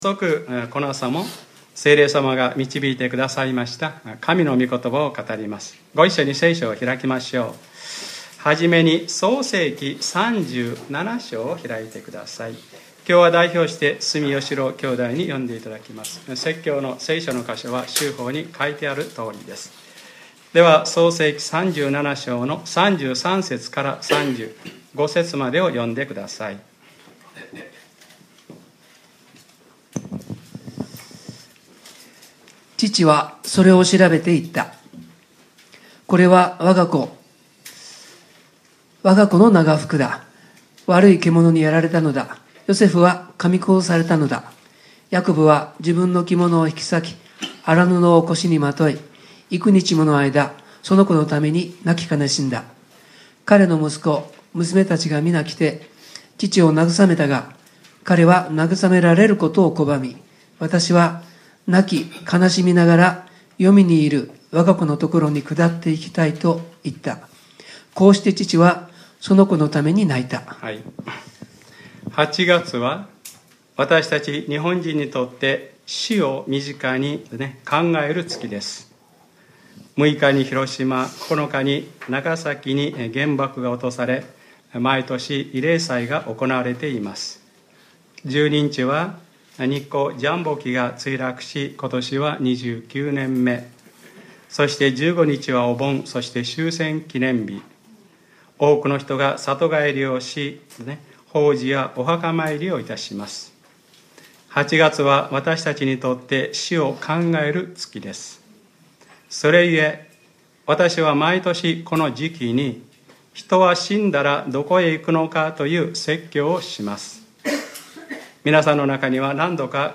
2014年8月17日（日）礼拝説教 『人は死んだら何処へ行くのか』